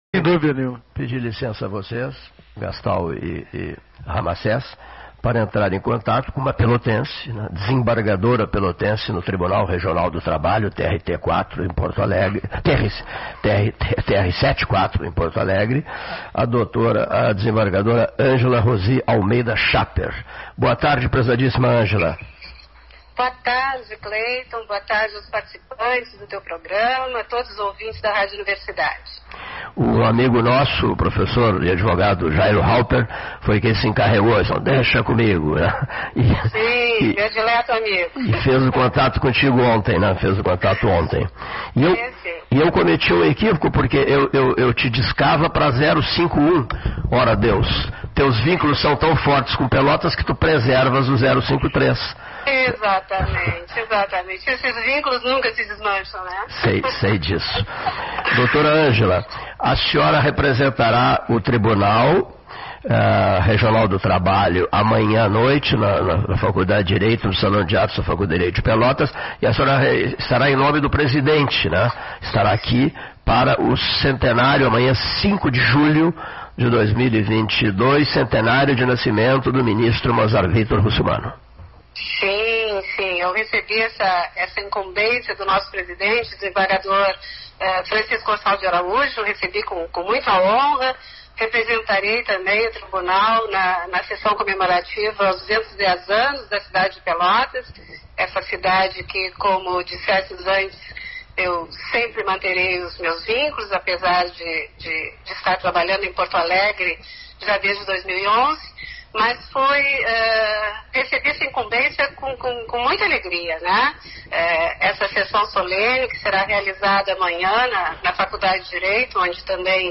Nessa segunda-feira, a desembargadora Angela Chapper concedeu uma entrevista ao programa Pelotas 13 Horas, da Rádio Universidade. A magistrada falou sobre as merecidas homenagens ao ministro Mozart Russomano.
Entrevista Angela Chapper.mp3